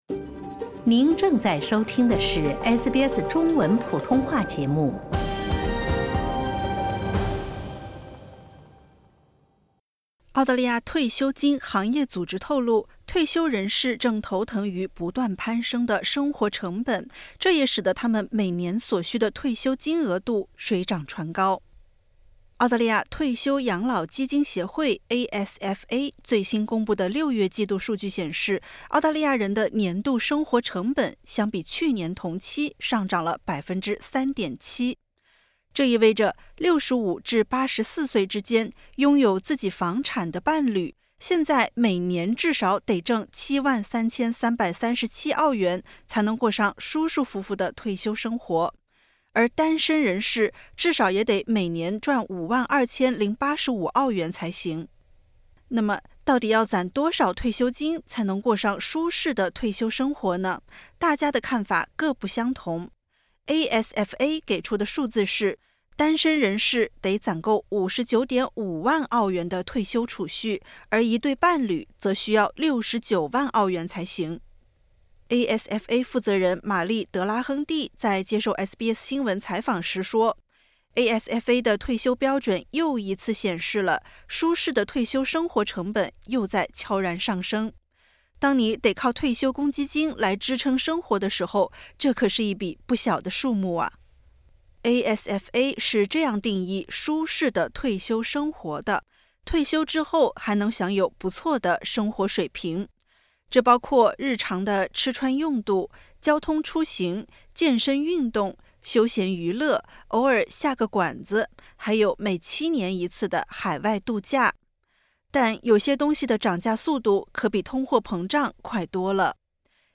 一份新出炉的报告揭示了答案：无论是单身贵族还是恩爱伴侣，要达到舒适的退休生活，每年都得赚够这么多钱。点击 ▶ 收听完整报道。